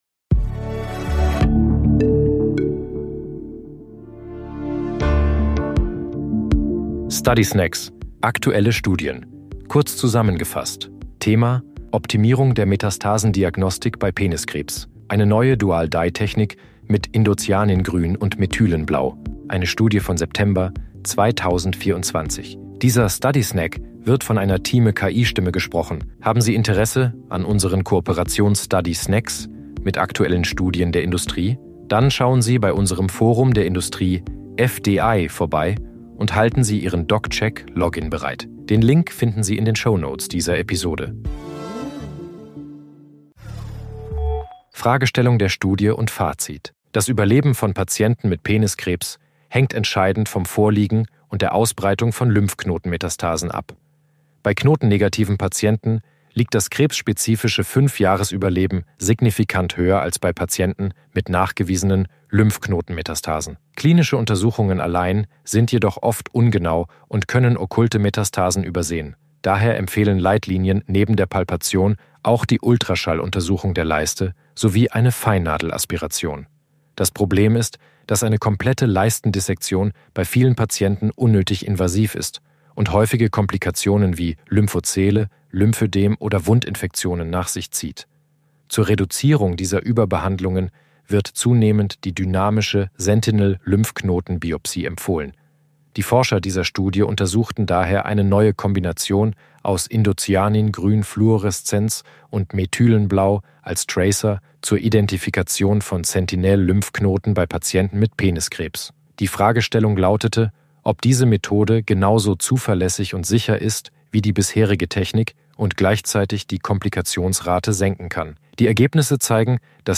Intelligenz (KI) oder maschineller Übersetzungstechnologie